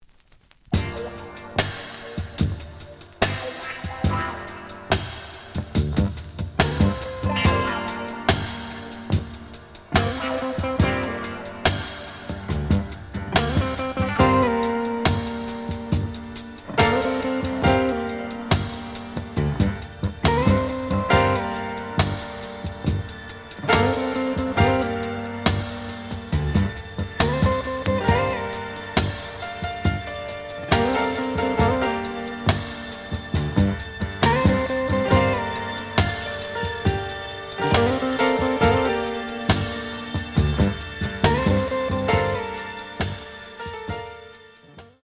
The audio sample is recorded from the actual item.
●Genre: Disco
Looks good, but slight noise on A side.)